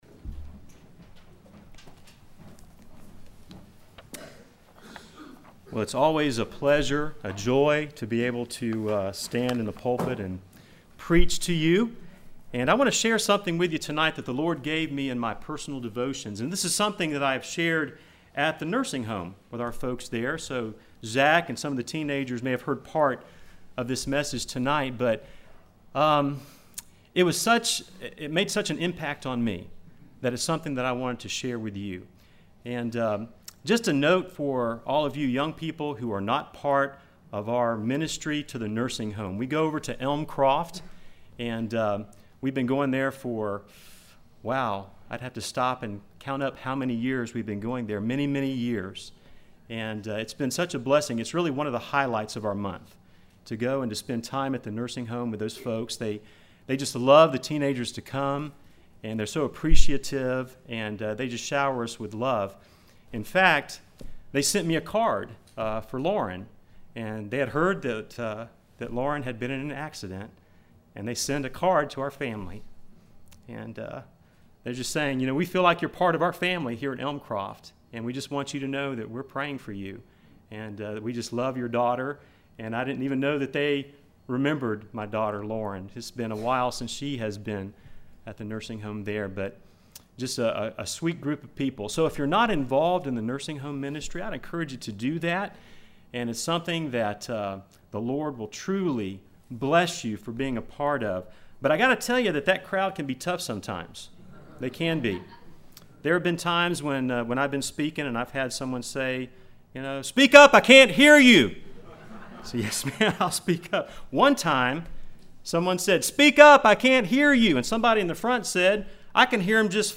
All sermons available in mp3 format